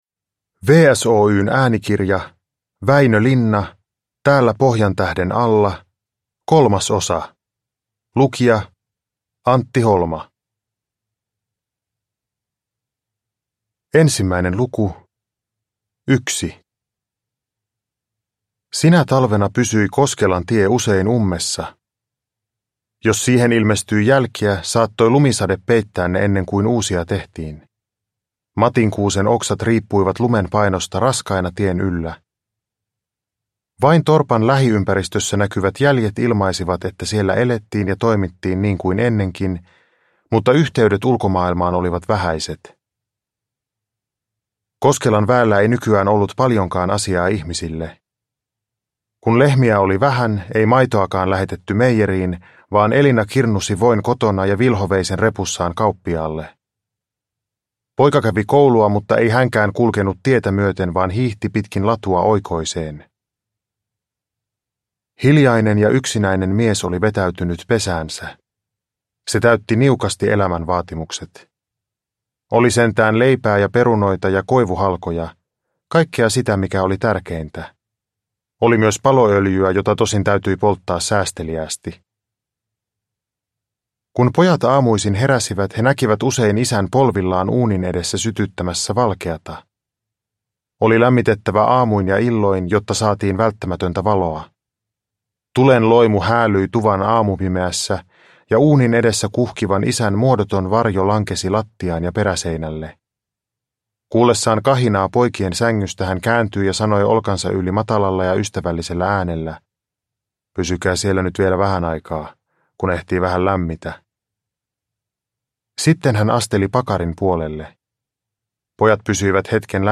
Uppläsare: Antti Holma